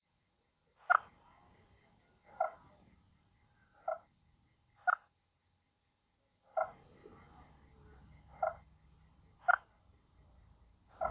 Tusked Frog
Call:
Described as a
soft 'p-tuck' or 'cluck'.
Males call from undercut banks, tree roots and overhanging vegetation, and crayfish burrows.
adelotus_brevis.mp3